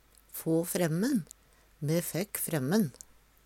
få fremmen - Numedalsmål (en-US)